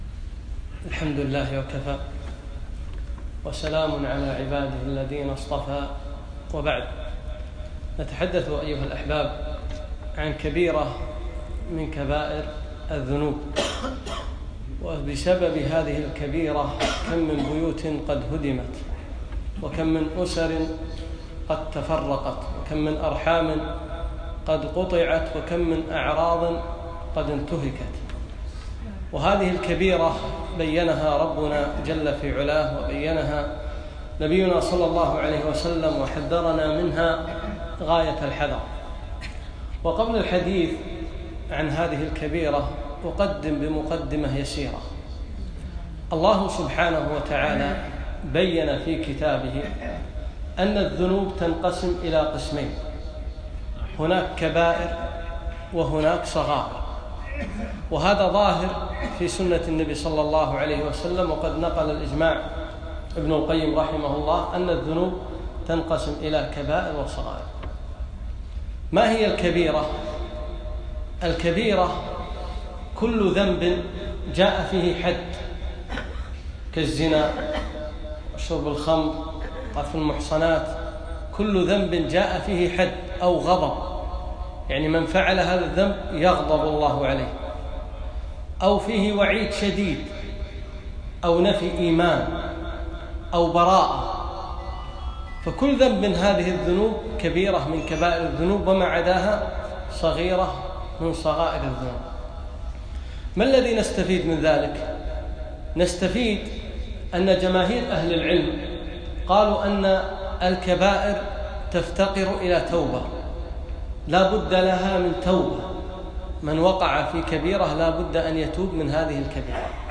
محاضرة - لعن الله الخمر